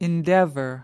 /ɪnˈdev·ər/